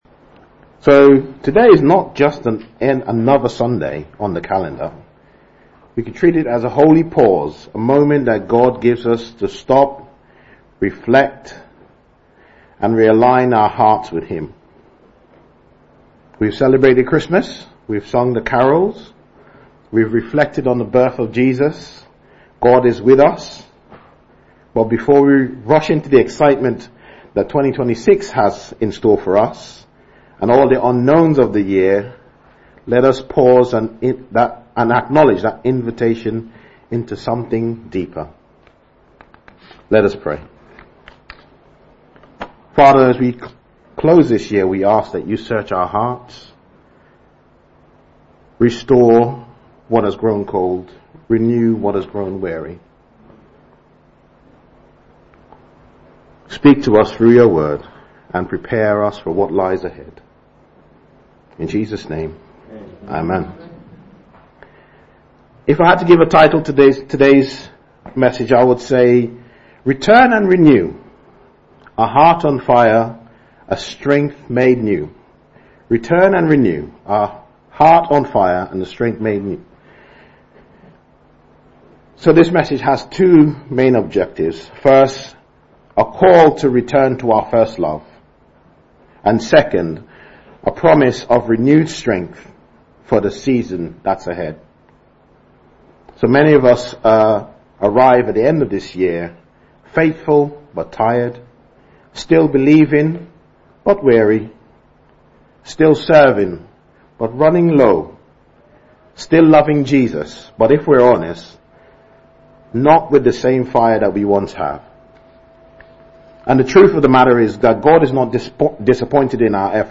GSC Bible Talks